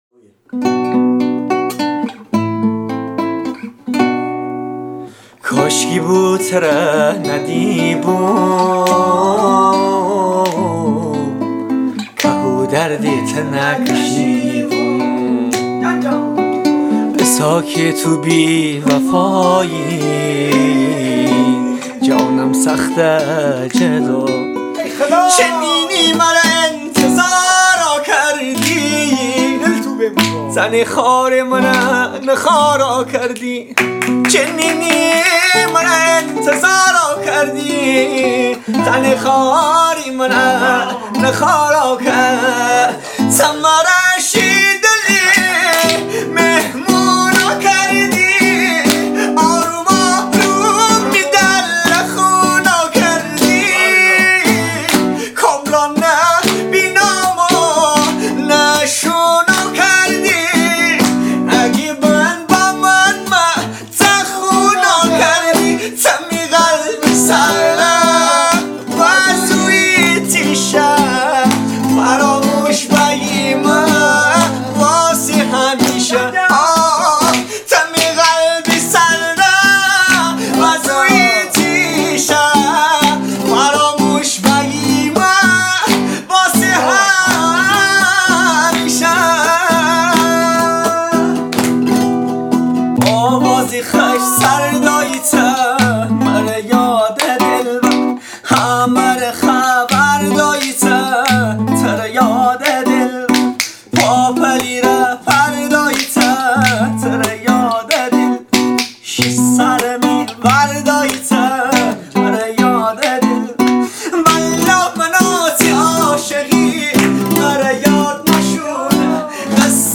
ریمیکس گیتاری